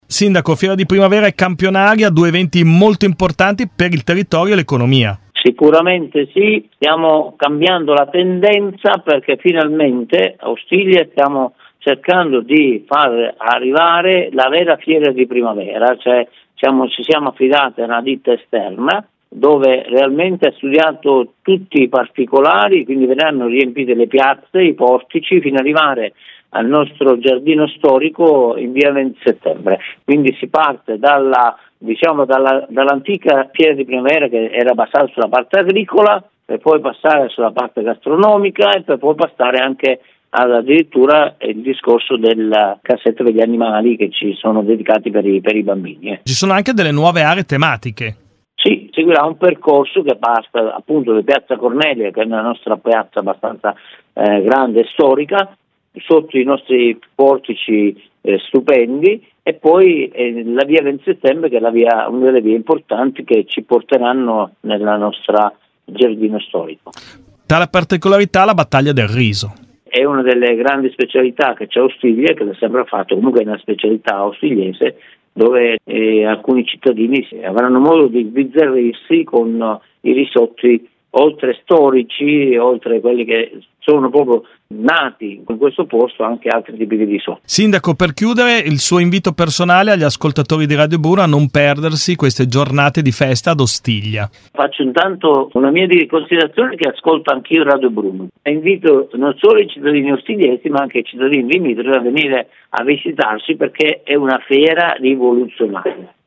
Ascolta la nostra intervista al Sindaco di Ostiglia (Mn) Luciano Barberio:
fiera-di-ostiglia-sindaco.mp3